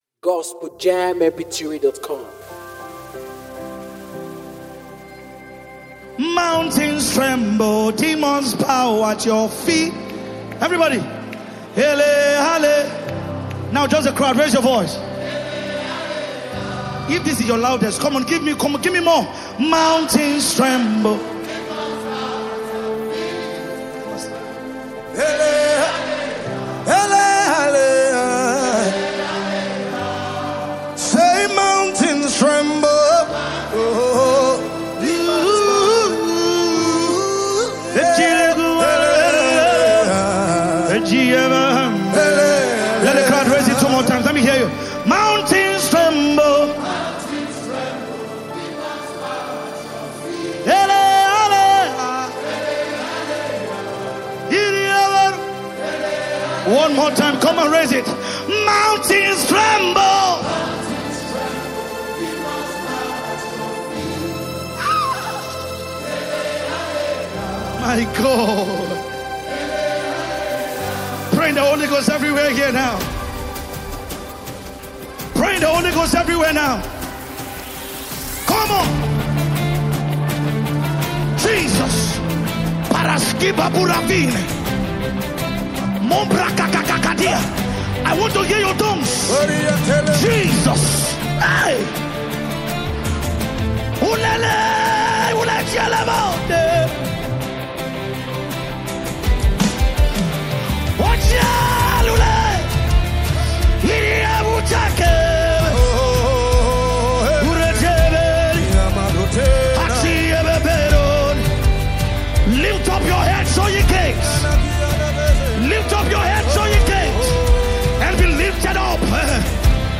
powerful gospel anthem
With passionate vocals and spirit-filled lyrics